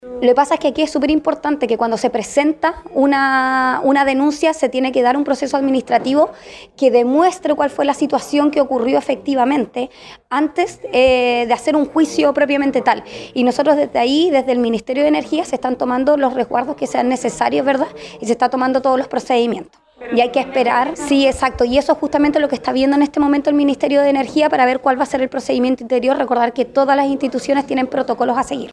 delegada-presidencial.mp3